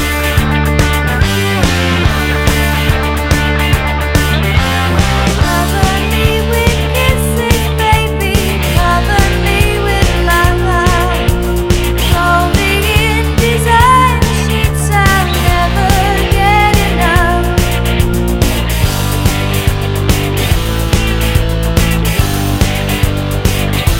One Semitone Down Pop (1980s) 3:29 Buy £1.50